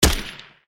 academic_skill_towergattling_fire_b.ogg